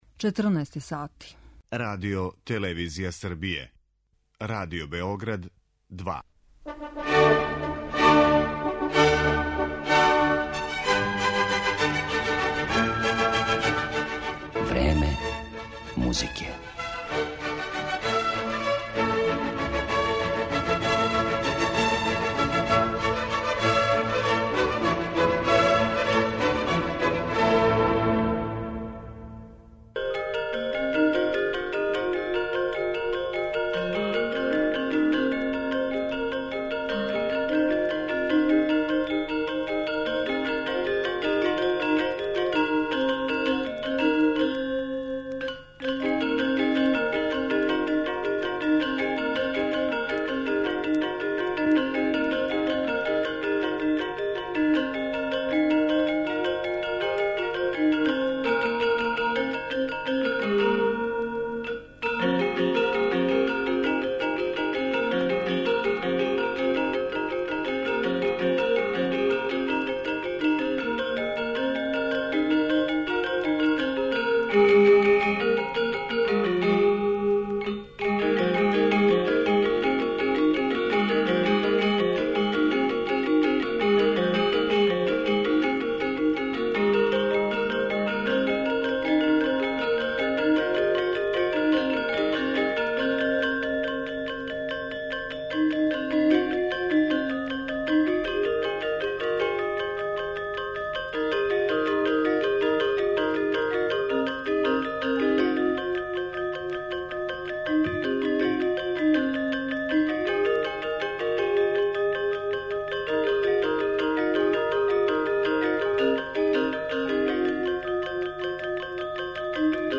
Звук традиционалног индонежанског ансамбла удараљки, познатог под називом гамелан, утицао је у последњих 100 и више година на бројне композиторе са Запада